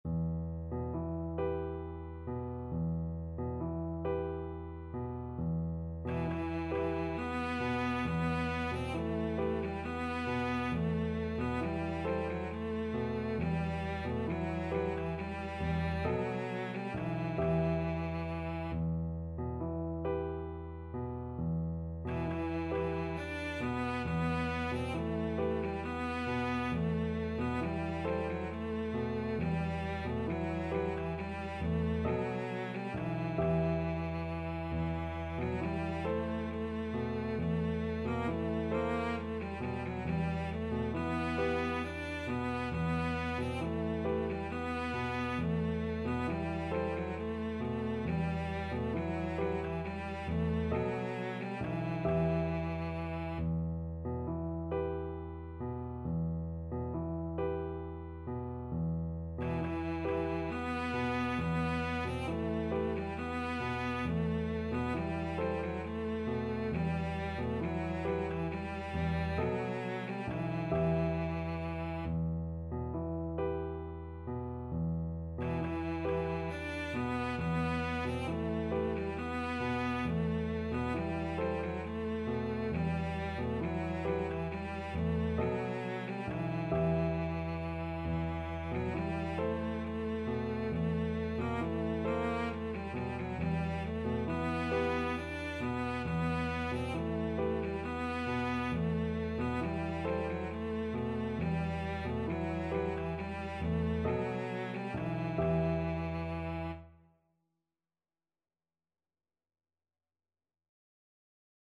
Cello
E minor (Sounding Pitch) (View more E minor Music for Cello )
6/8 (View more 6/8 Music)
Gently rocking .=c.45
E4-D5
Classical (View more Classical Cello Music)
Turkish